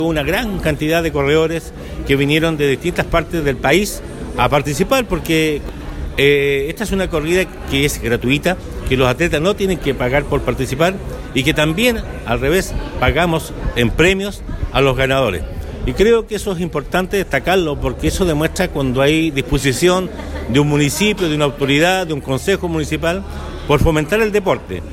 El éxito de esta Media Maratón fue destacado por el alcalde Emeterio Carrillo ya que participaron atletas de todo el país, lo que refleja el sitial alcanzado por el evento.